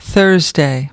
5.Thursday  /ˈθɝːz.deɪ/ : thứ năm